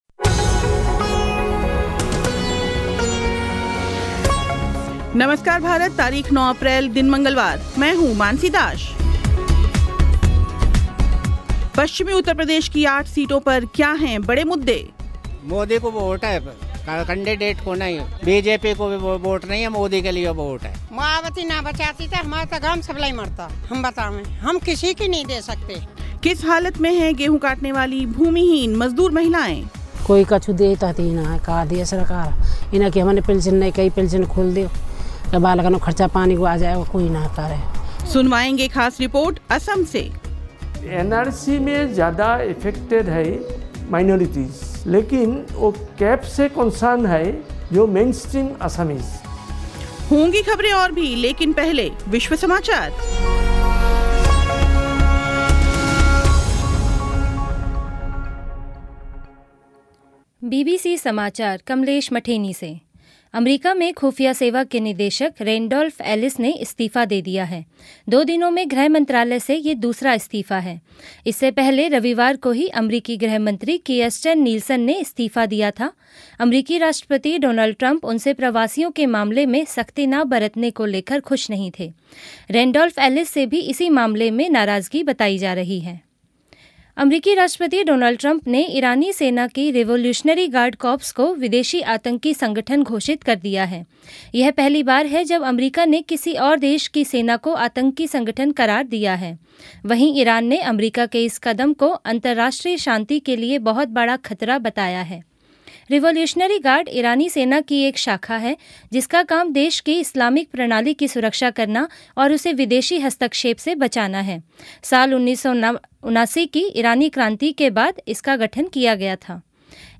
ख़ास रिपोर्ट असम से खेल समाचार